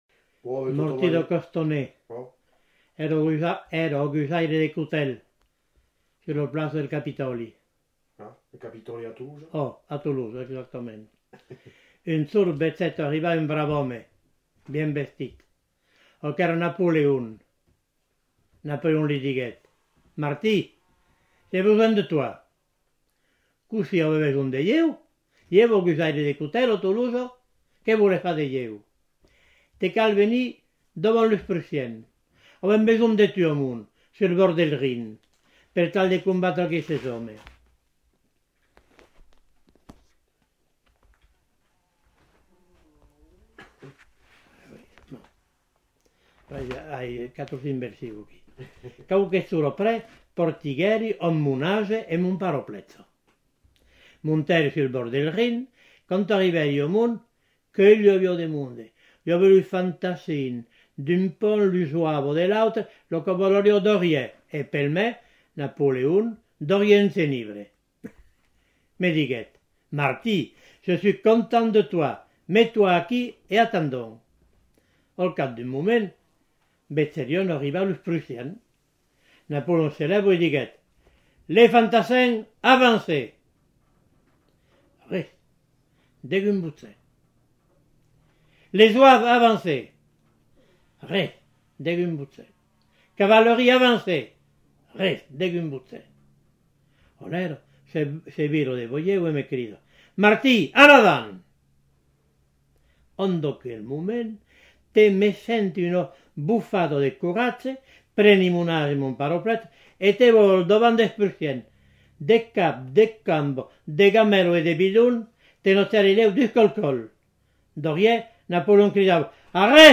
Lieu : Rocamadour
Genre : conte-légende-récit
Type de voix : voix d'homme Production du son : lu Classification : menteries (vantardises)